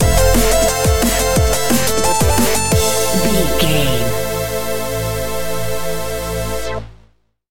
Epic / Action
Fast paced
Aeolian/Minor
aggressive
dark
driving
energetic
futuristic
synthesiser
drum machine
electronic
sub bass
synth leads